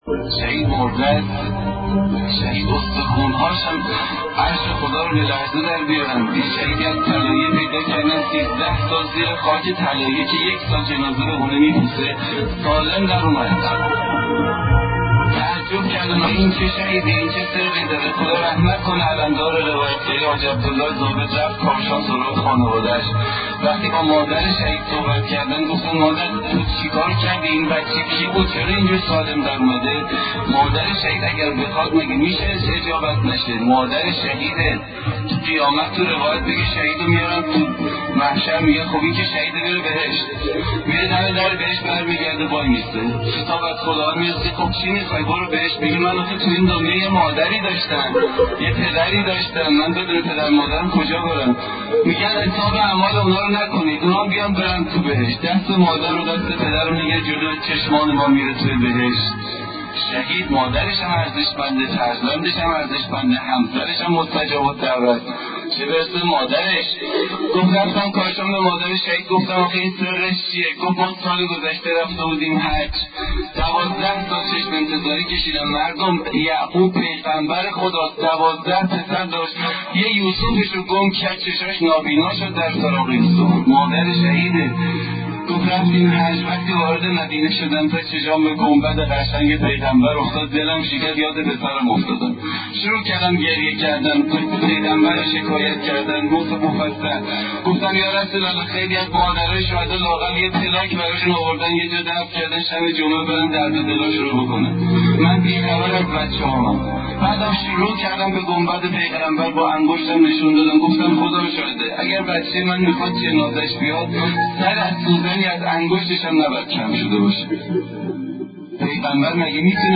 صوت روایتگری
ravayatgari188.mp3